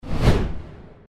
logoimpact1.mp3